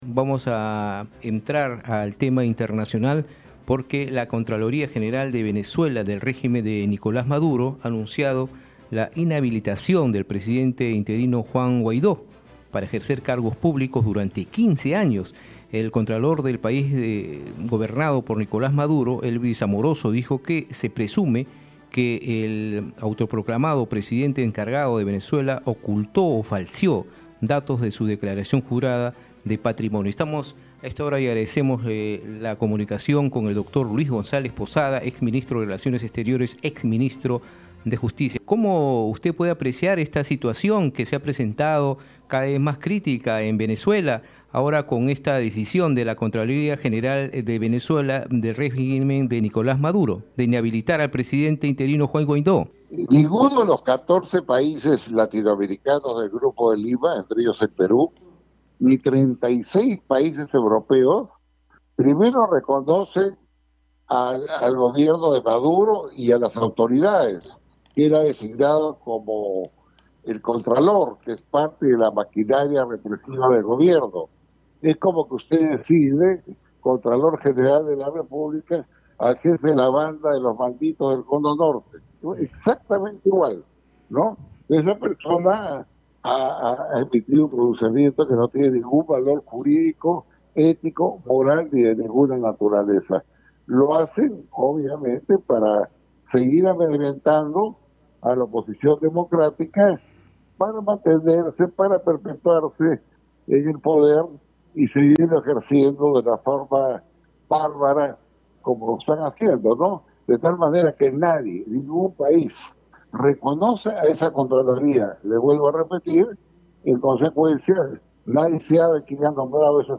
En declaraciones a RCR, Red de Comunicación Regional, el exministro aprista dijo que entre los 707 mil venezolanos que han llegado a nuestro país, algunos delincuentes habrían conseguido ingresar y se requiere tomar medidas al respecto.